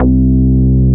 P MOOG C3MP.wav